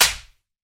Slap3.wav